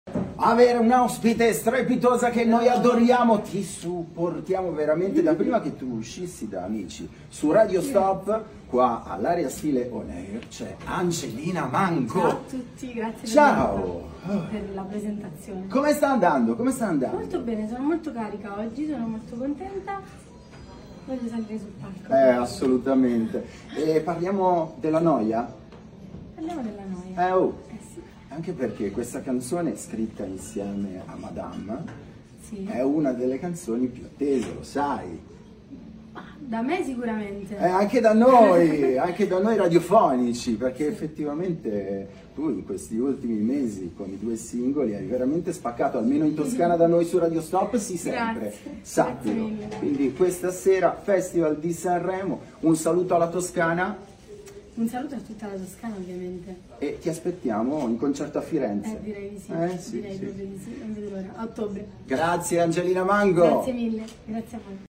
Festival di Sanremo con Radio Stop!
Radio Stop – Intervista a ANGELINA MANGO
Intervista-a-ANGELINA-MANGO.mp3